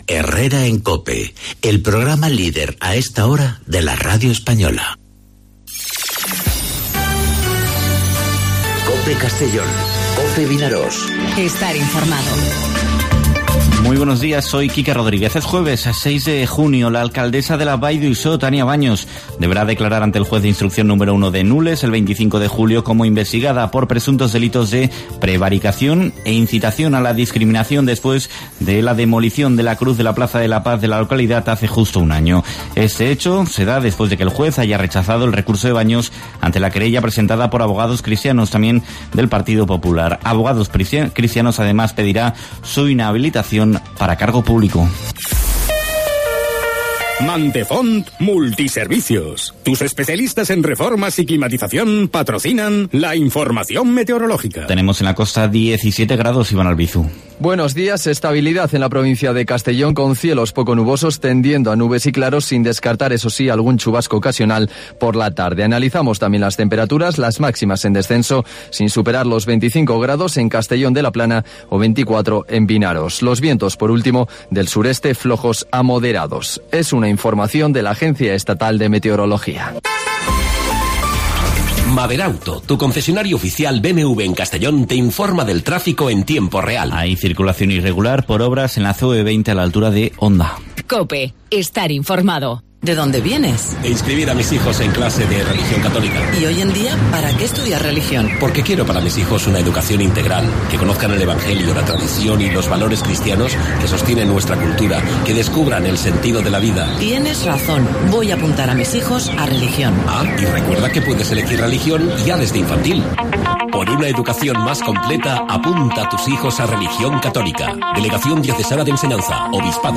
Informativo 'Herrera en COPE' Castellón (06/06/2019)